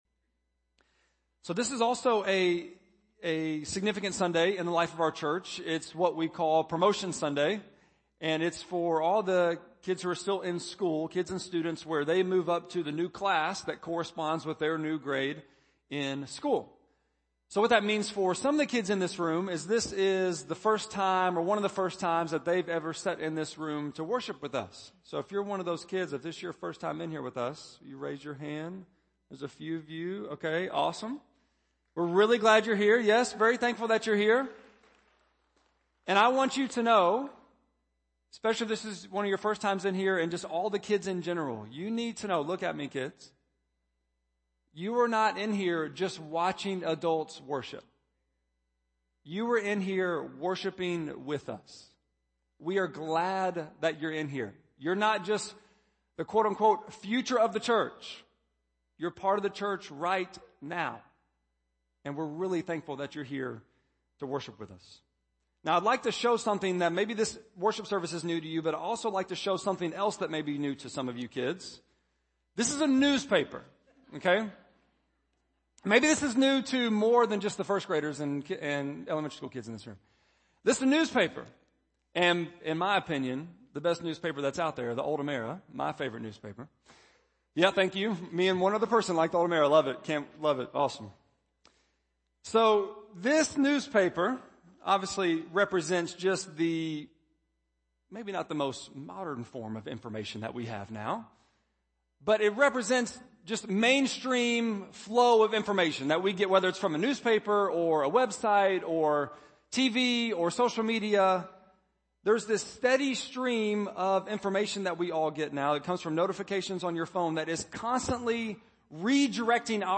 8.15-sermon.mp3